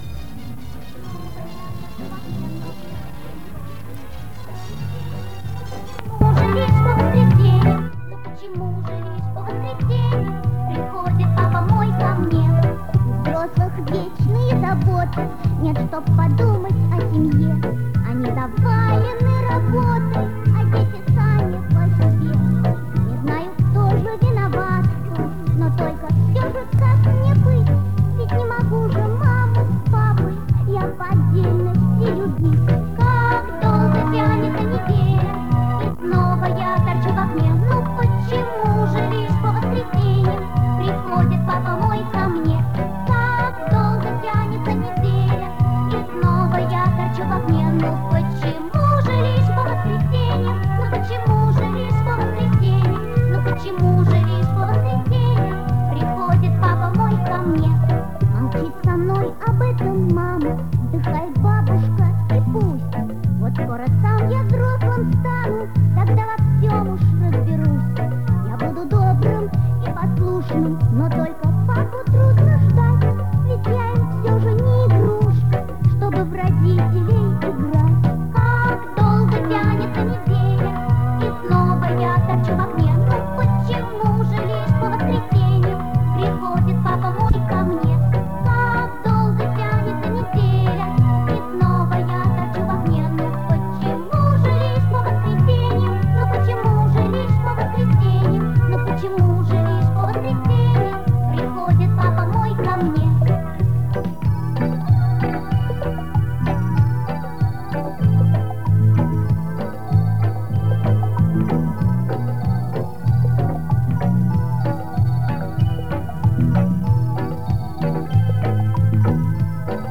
Возможно скорость не та (или быстрее или медленнее) , но это то что осталось.